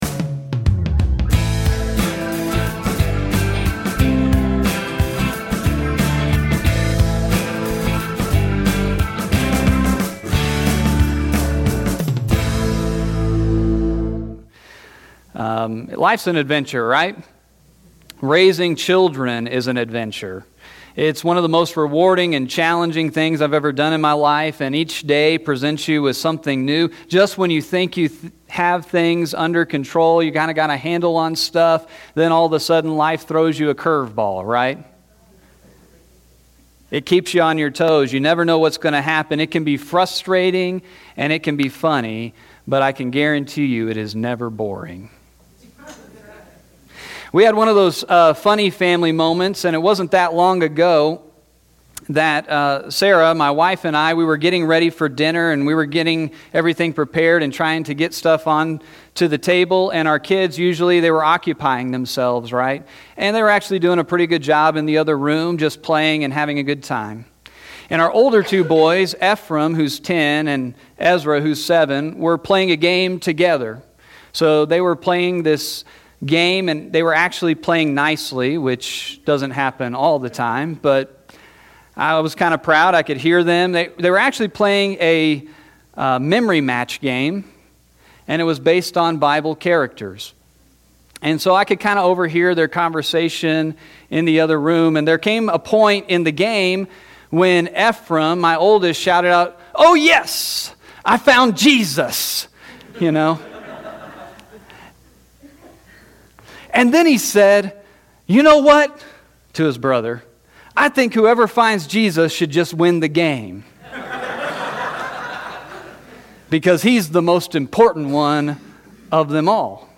Play Rate Listened List Bookmark Get this podcast via API From The Podcast Sermons from the NazFamily Church in Big Spring, Texas.